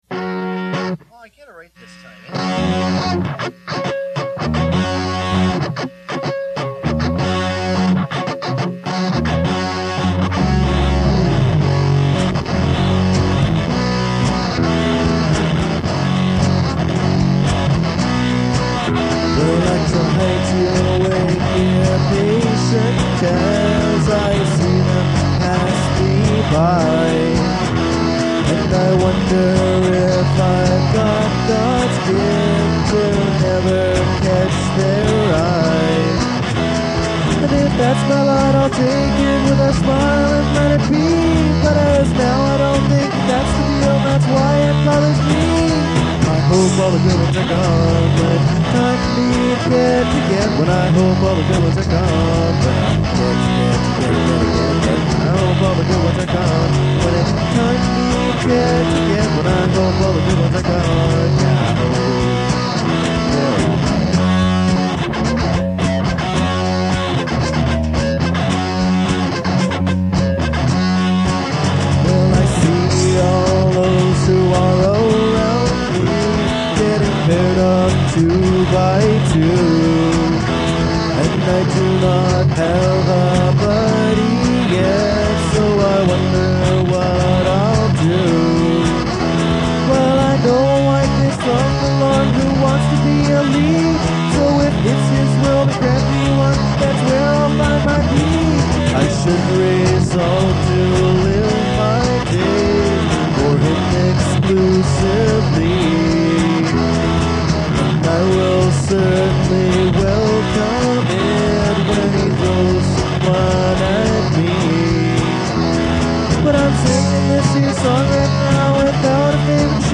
Of course, he was right – there aren’t enough drums in the mix (no kick drum at all, I think, though maybe we used a drum machine for a substitute – real snare and cymbal anyway). We’re also not all that tight.
great guitar tone